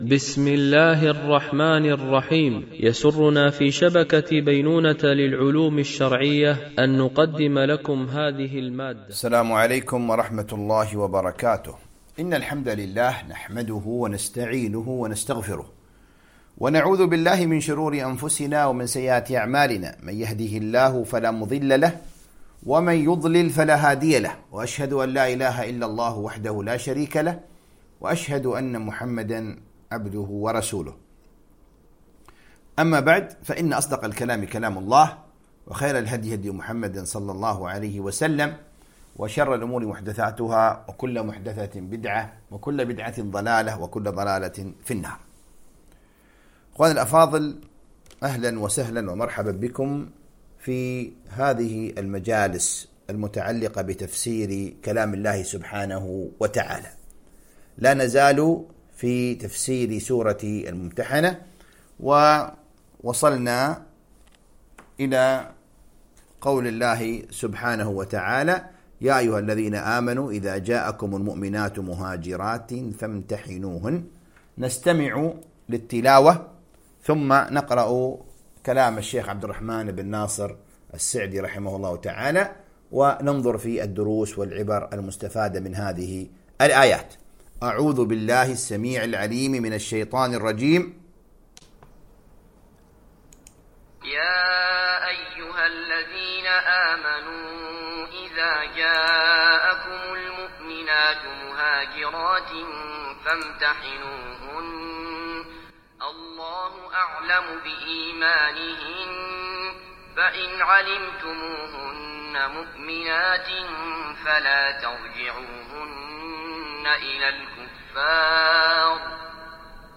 القسم: التفسير